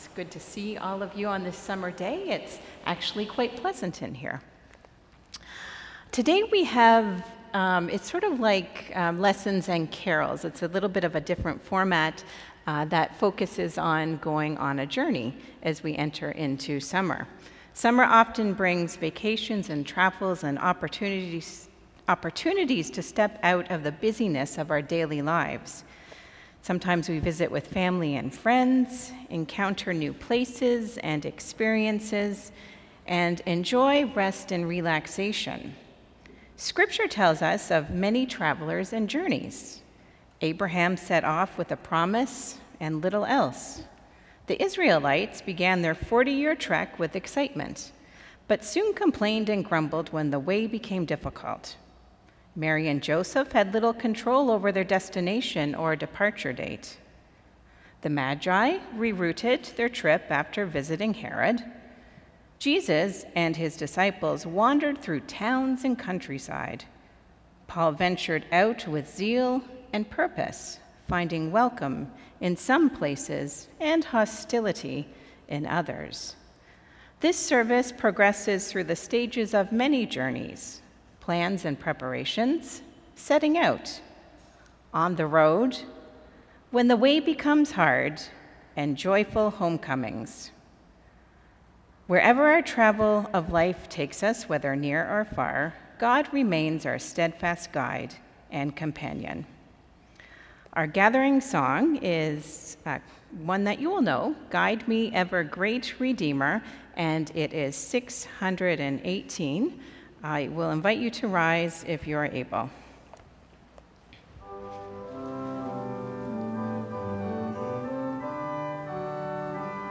Worship Service Sunday, July 6, 2025